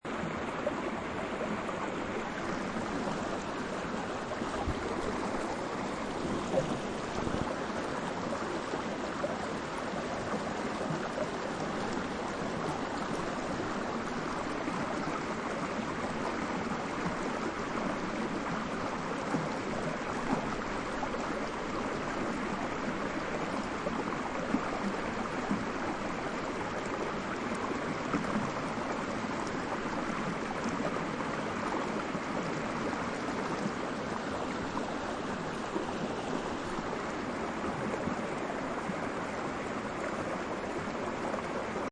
Canada Glacier Melt Waterfalls
The sound of the Canada glacier streams and waterfalls dominated the soundscape of Lake Hoare.
canada_falls_percussion_stereo_good.mp3